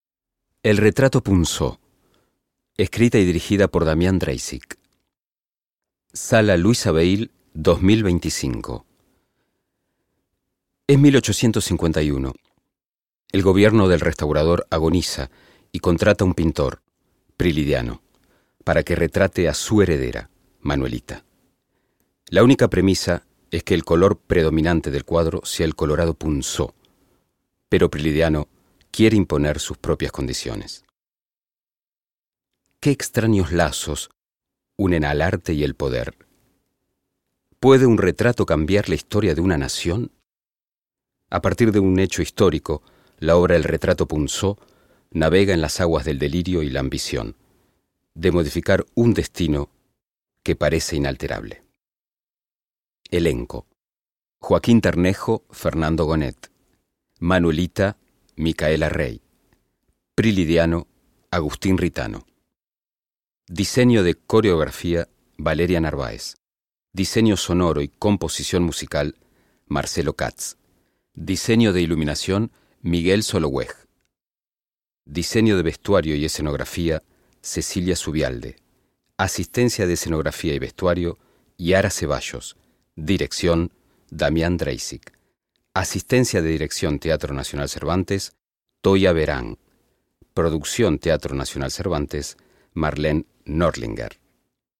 lectura del programa de mano